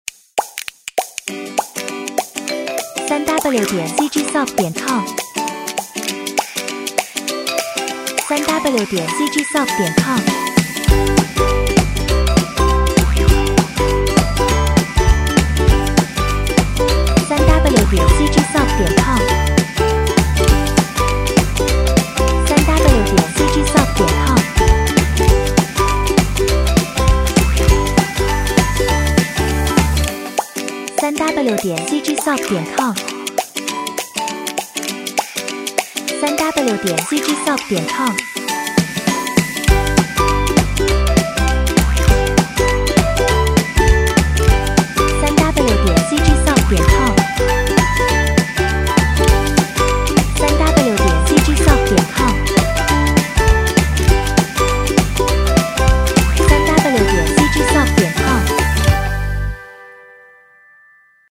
尤克里里，铃铛，原声鼓，拍手，口哨
16位立体声，44.1 kHz
时间（BPM）:100